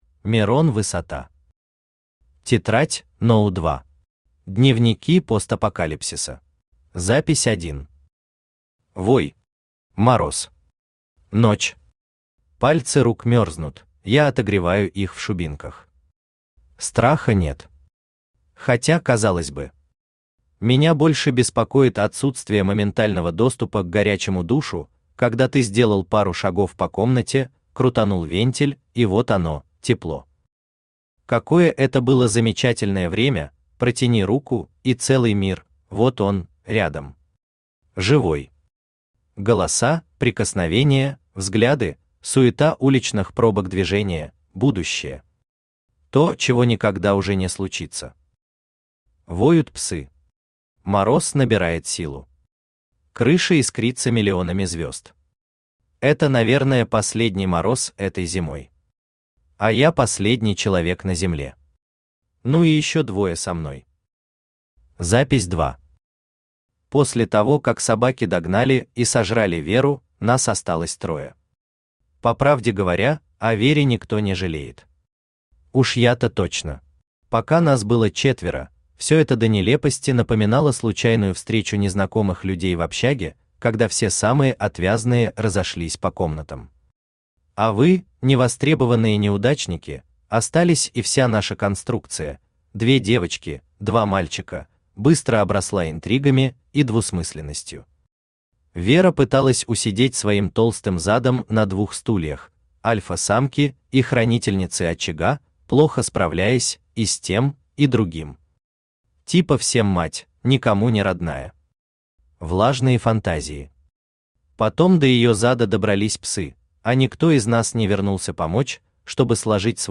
Дневники постапокалипсиса Автор Мирон Высота Читает аудиокнигу Авточтец ЛитРес.